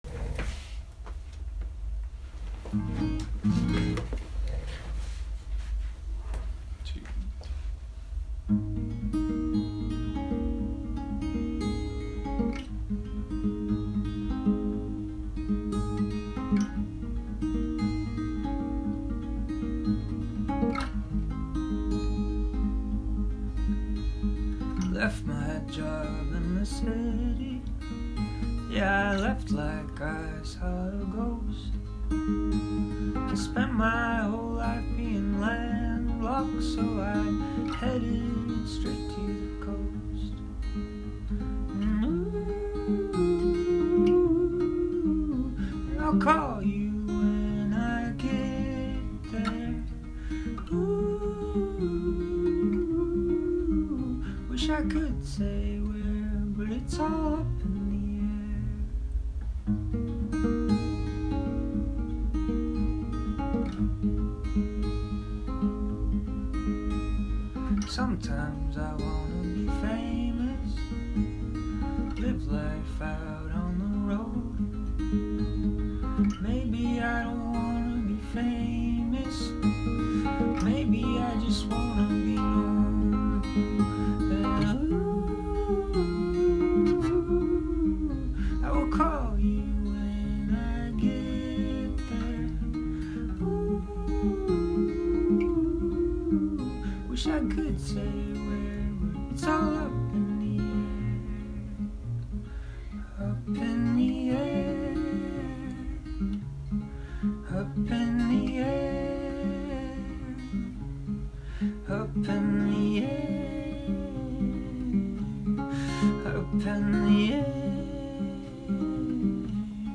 Voice memo of “Up in the Air”
I love her harmonies.
This is what we call in “the biz” a worktape: